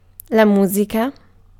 Ääntäminen
UK : IPA : /ˈmjuːzɪk/ US : IPA : /ˈmjuzɪk/